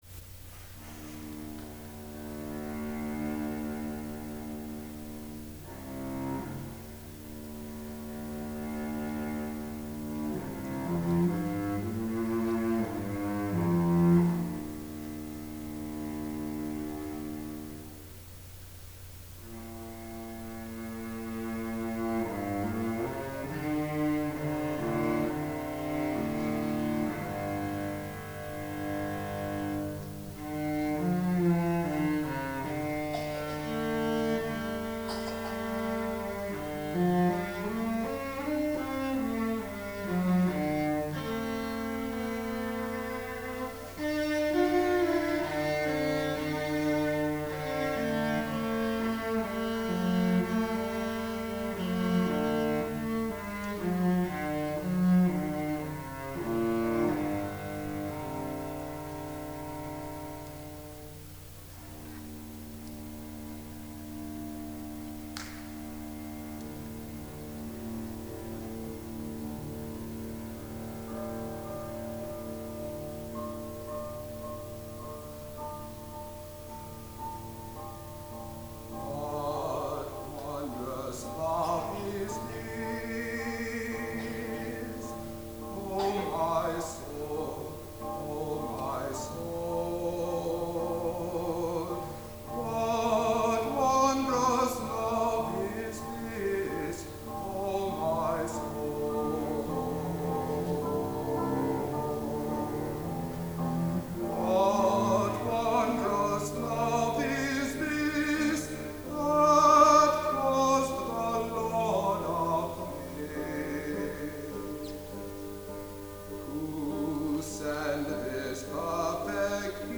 for SATB Chorus, Cello, and Piano (1993)
cello